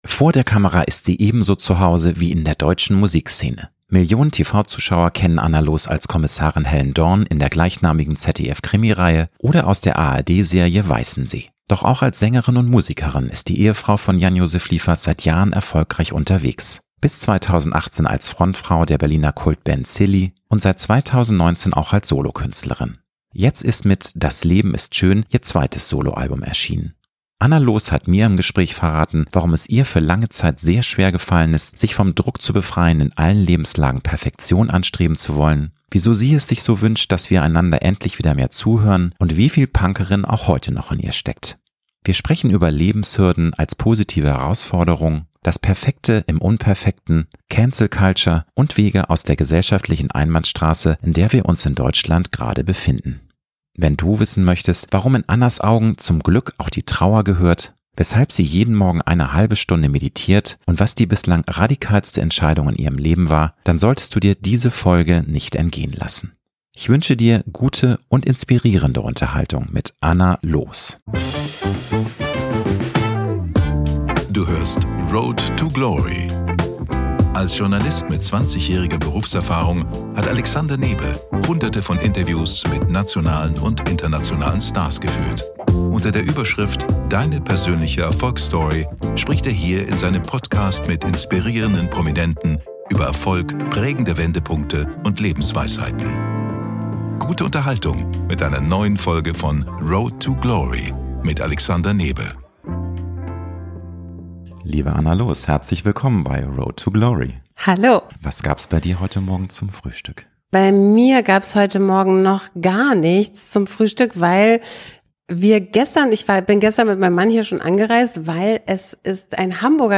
Promi-Talk
Anna Loos hat mir im Gespräch verraten, warum es ihr für lange Zeit sehr schwergefallen ist, sich von dem Druck zu befreien, in allen Lebenslagen Perfektion anstreben zu wollen, wieso sie es sich so wünscht, dass wir einander endlich wieder mehr zuhören und wieviel Punkerin auch heute noch in ihr steckt. Wir sprechen über Lebenshürden als positive Herausforderung, das Perfekte im Unperfekten, Cancel-Culture und Wege aus der gesellschaftlichen Einbahnstraße, in der wir uns in Deutschland gerade befinden.